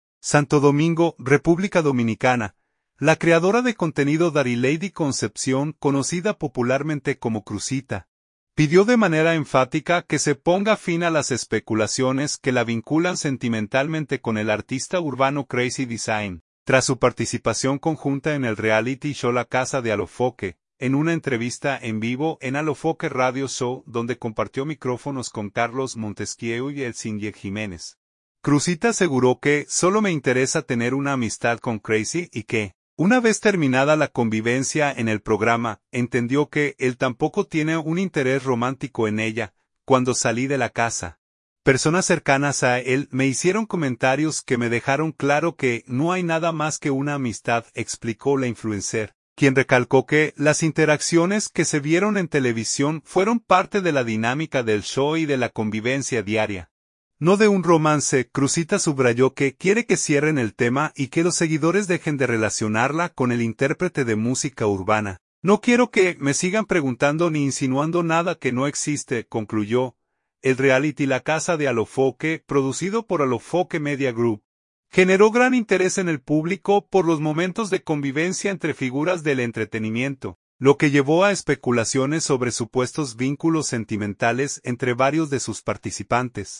En una entrevista en vivo en Alofoke Radio Show